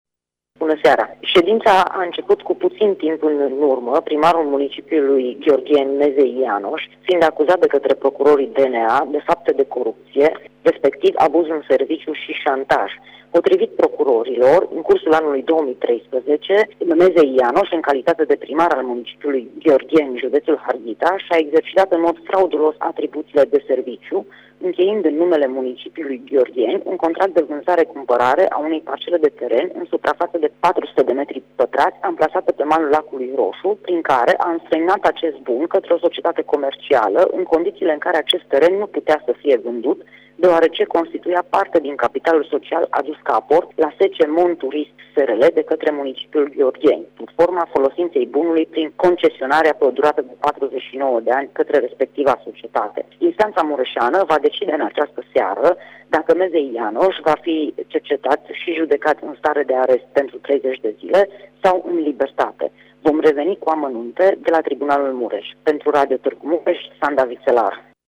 Ne spune mai multe, reporterul nostru la faţa locului